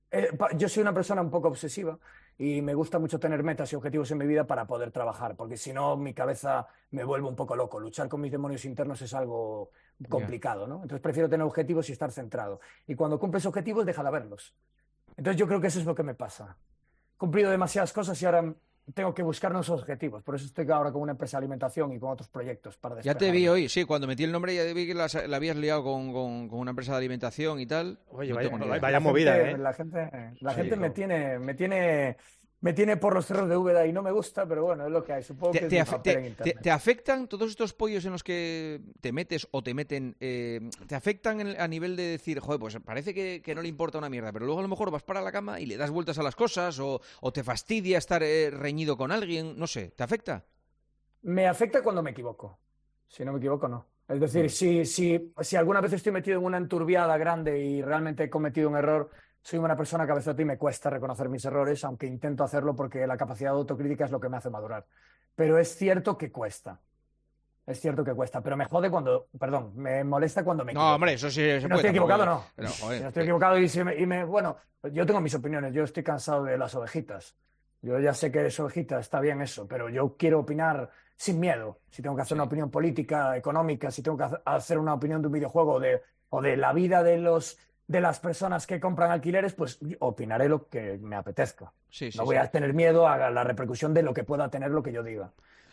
Juanma Castaño y ElXokas
El streamer fue muy transparente en El Partidazo de COPE, contestando a todas las preguntas que le hicieron también los tertulianos.